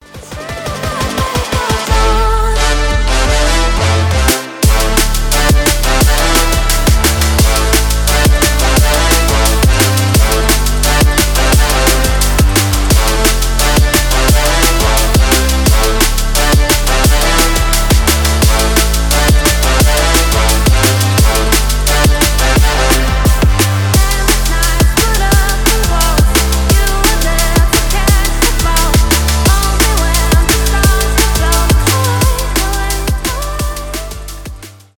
красивый женский голос , future bass , дабстеп , edm